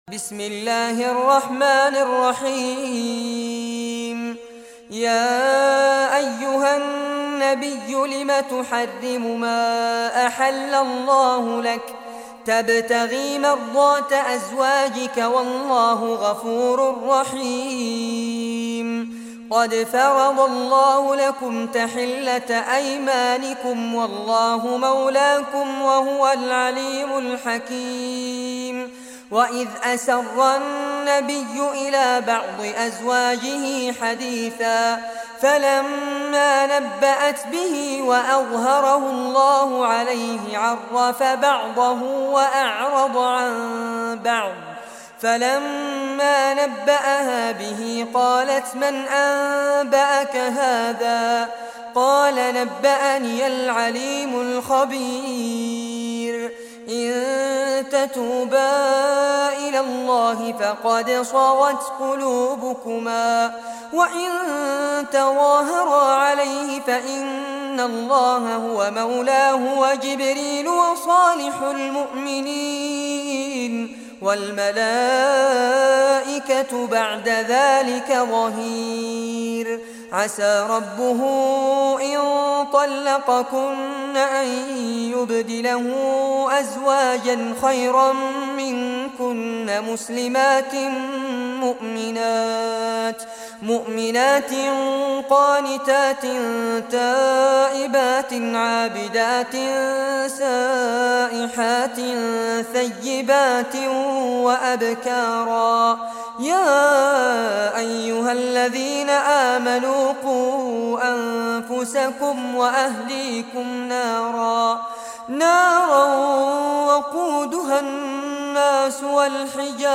Surah Tahrim Recitation by Fares Abbad
Surah At-Tahrim, listen or play online mp3 tilawat / recitation in Arabic in the beautiful voice of Sheikh Fares Abbad.